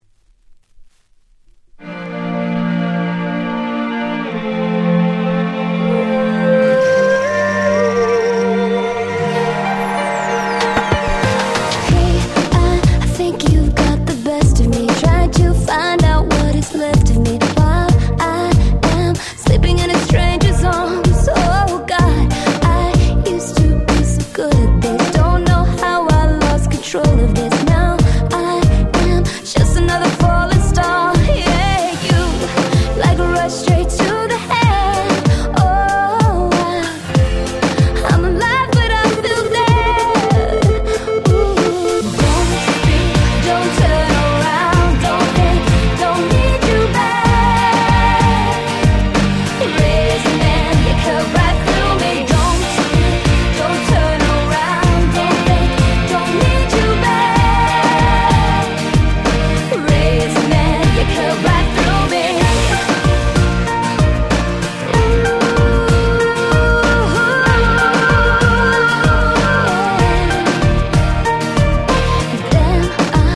08' Nice EU R&B !!
キャッチー系